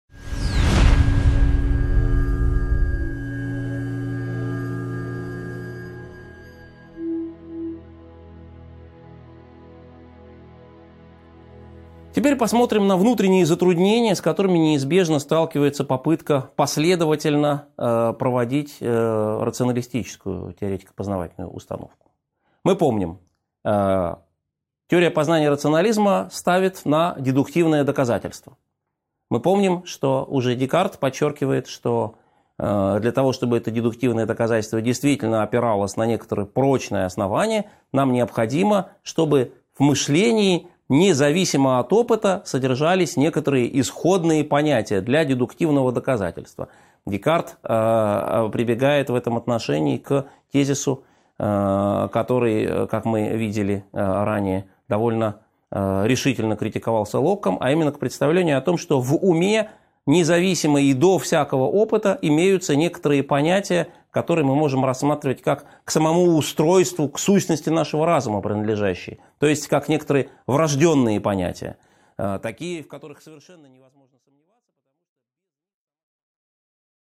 Аудиокнига 9.8 Проблемы программы рационализма | Библиотека аудиокниг